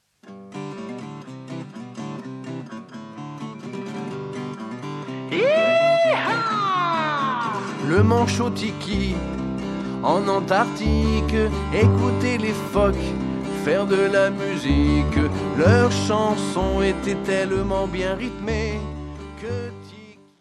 CD de chansons pour enfants